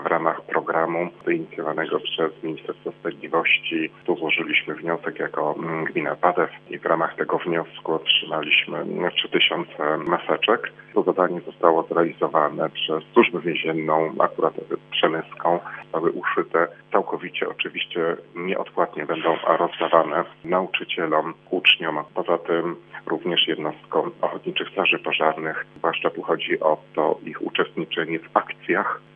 Mówi wójt gminy Robert Pluta.